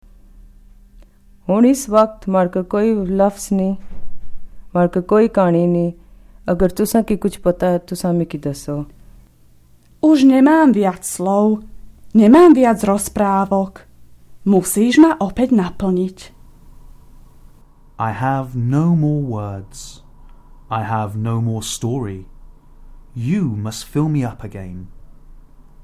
These are phrases that emerge from the story-telling box in the programme...